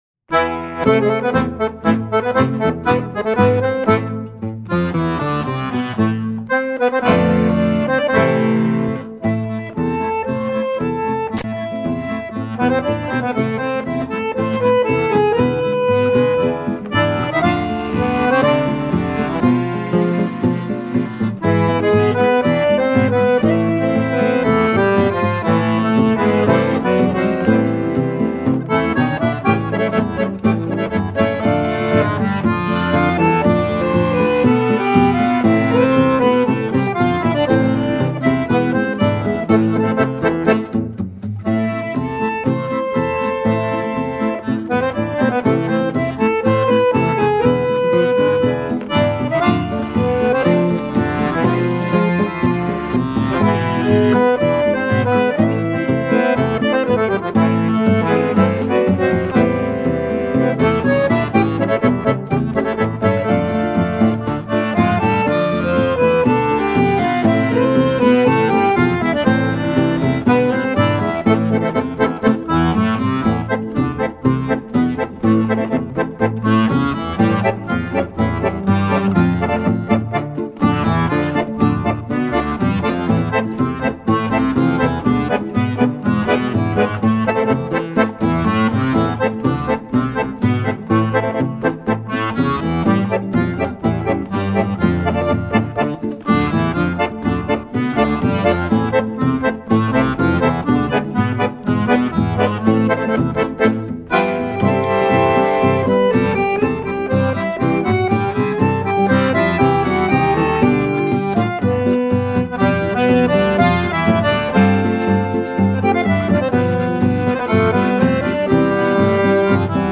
Acoordeon
Violao Acústico 6